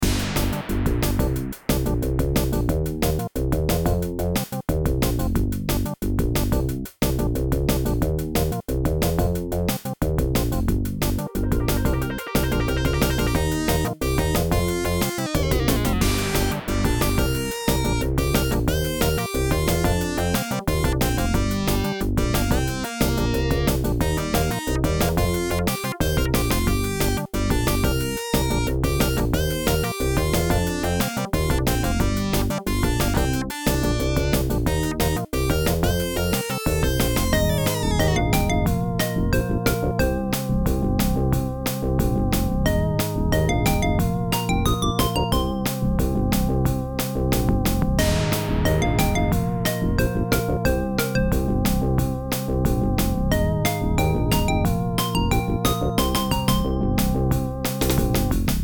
pra um simples convite essa faixa mesmo (que é loopável) já é uma boa